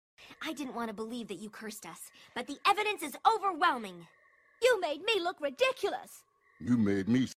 cube sounds like a man sound effects free download